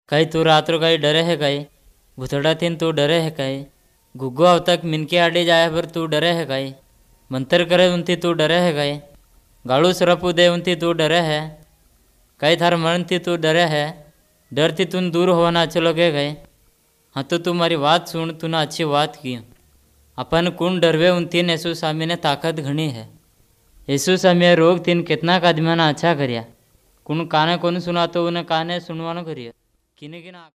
Most use a storytelling approach. These are recorded by mother-tongue speakers